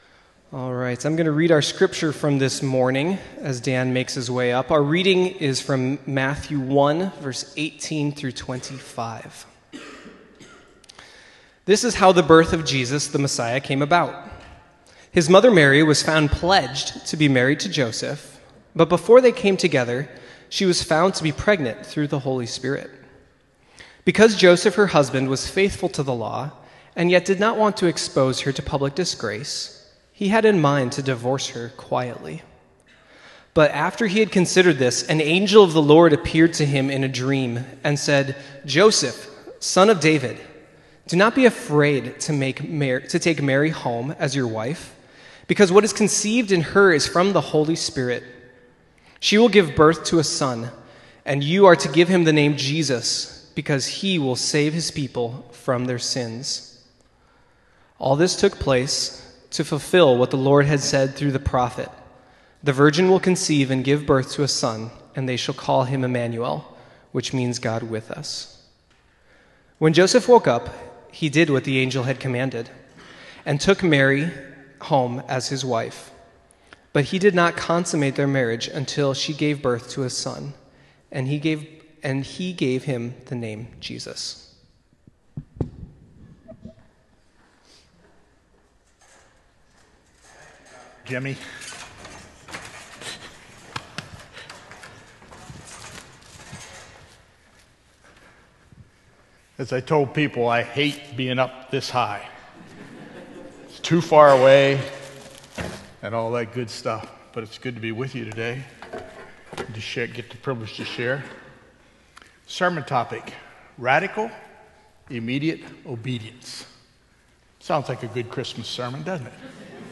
sermon-radical-immediate-obedience.m4a